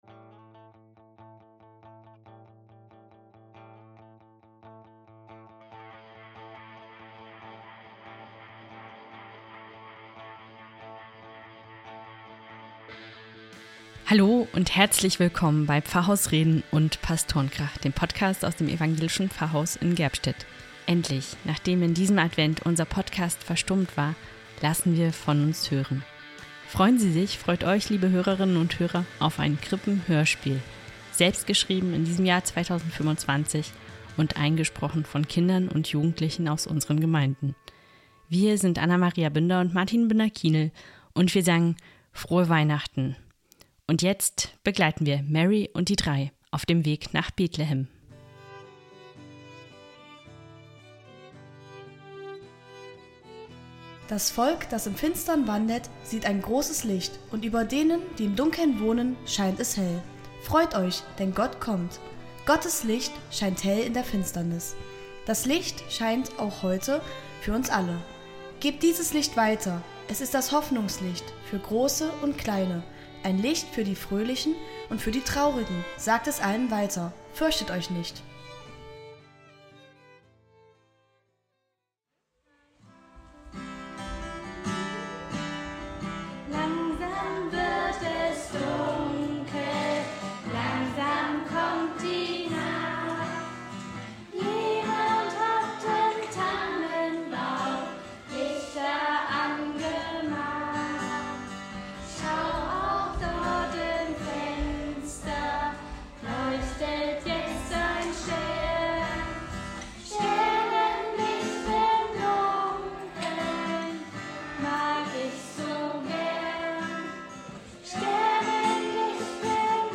Ein Krippenspiel zum Hören. Über ein Mädchen aus der Zukunft und ihr Abenteuer mit den drei Sterndeutern Caspar, Melchior und Balthasar.
Ein Krippenhörspiel